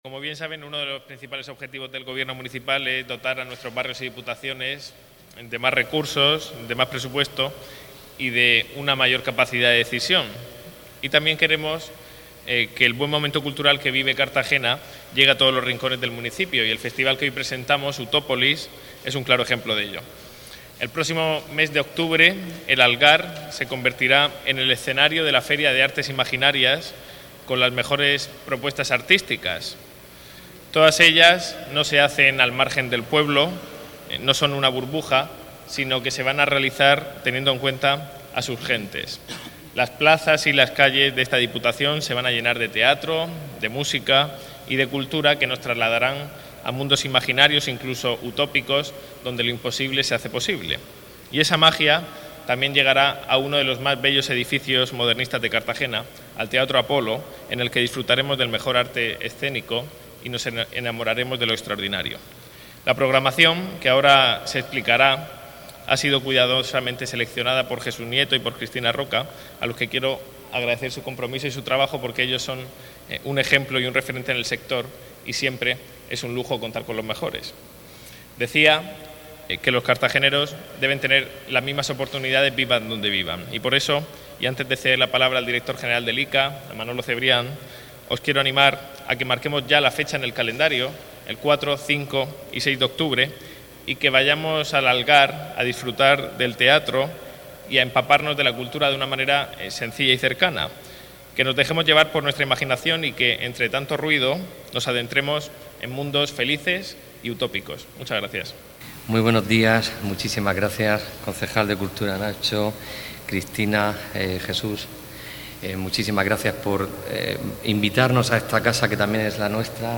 Enlace a Presentación de la feria de artes Utópolis